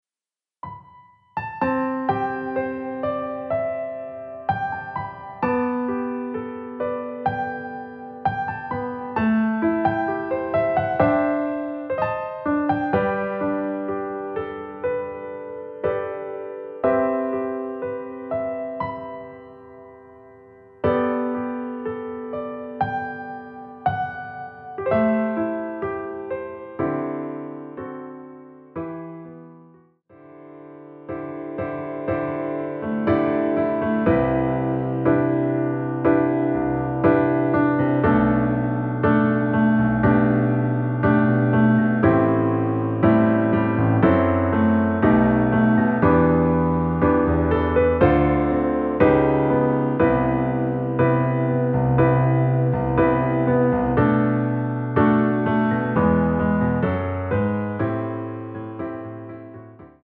여성분이 부르실수 있는 키로 제작 되었습니다.(미리듣기 참조)
앞부분30초, 뒷부분30초씩 편집해서 올려 드리고 있습니다.
중간에 음이 끈어지고 다시 나오는 이유는